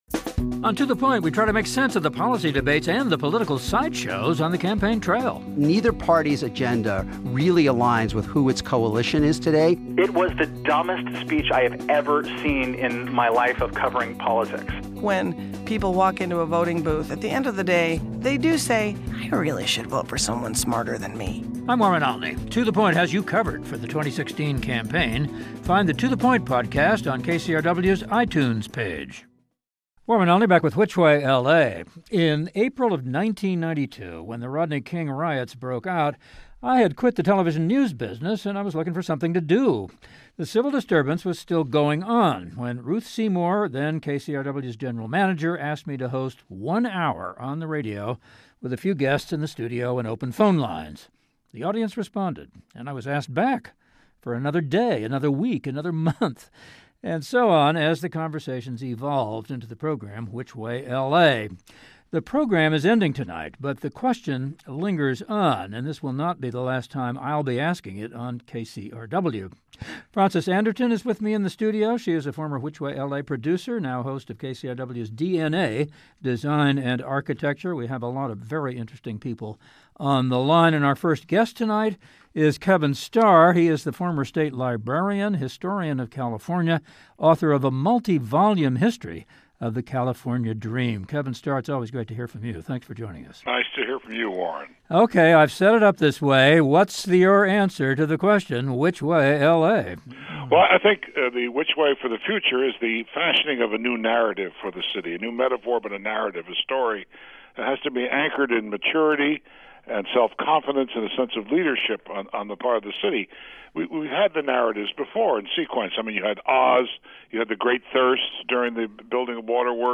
While this is the last program titled WWLA? the question still needs to be asked. We talk with a group of important and thoughtful people about what LA has become and about the challenges to be faced in the future…as we continue.